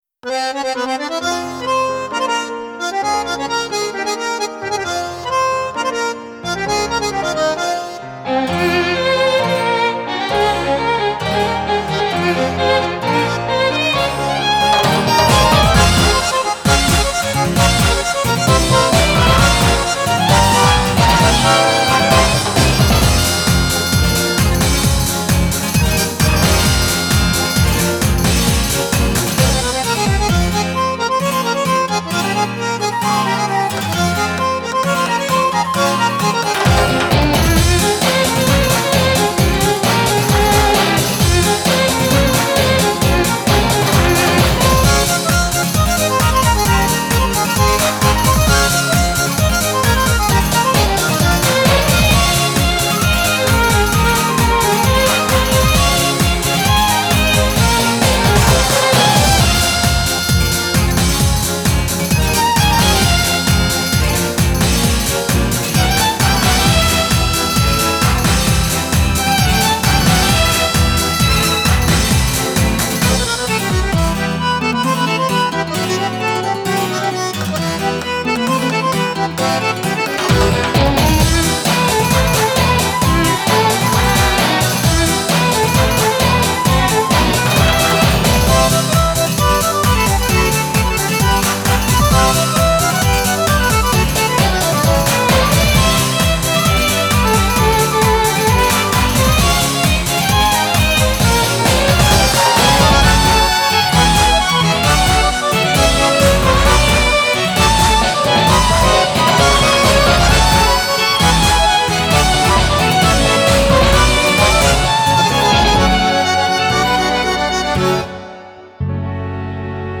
BPM57-132
Audio QualityMusic Cut